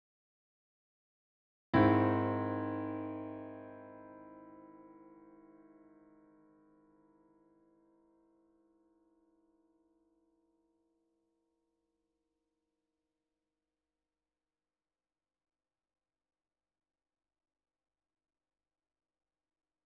It ticked quietly, and at appropriate times it stroke - once every half hour, and an adequate number of times at full hours. It stroke slowly, not too loudly, with a deep sound that made up a bell-like chord - tempered unevenly, major and minor at the same time, in fact, as if augmented.
It sounded exciting and melancholic at the same time, as if it was heralding everything that was yet to come, but expressed deep regret after all this already.
It never ended, it resounded for a long time, fading out very slowly and blending in, or perhaps, melting into, various other sounds.